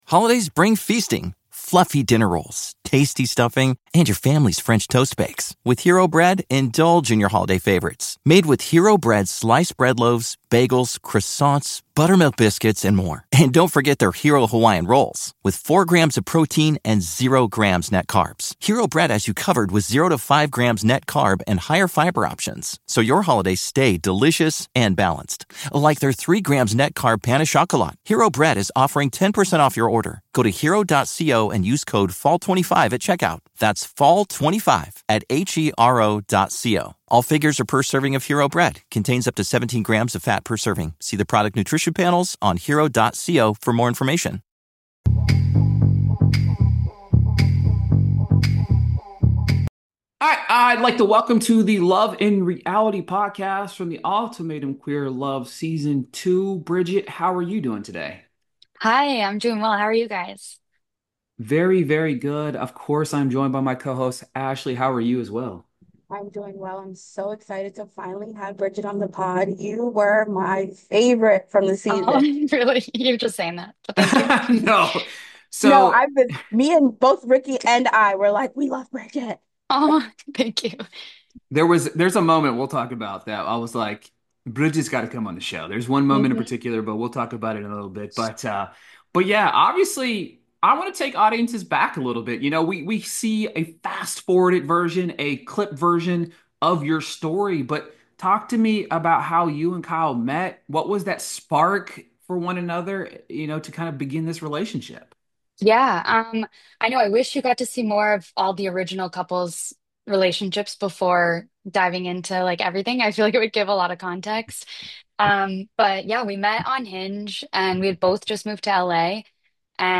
Interview | The Ultimatum: Queer Love Season 2